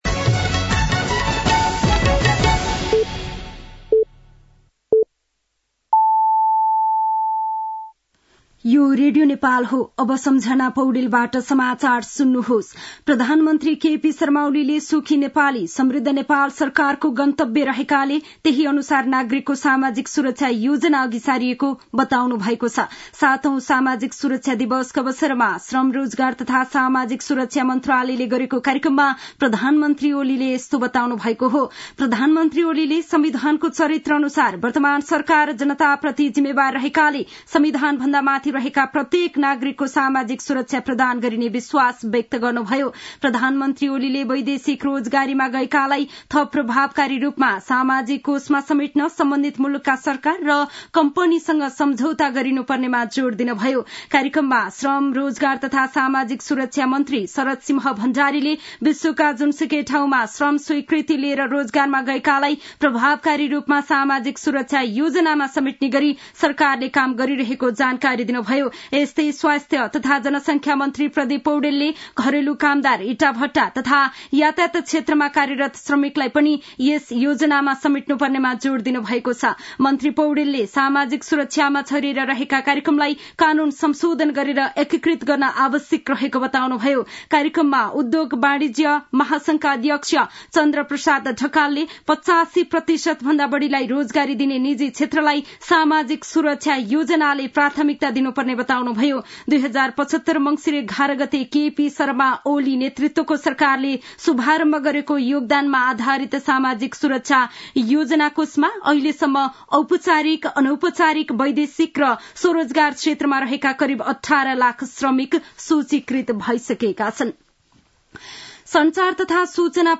दिउँसो ४ बजेको नेपाली समाचार : १२ मंसिर , २०८१
4-pm-Nepali-news-.mp3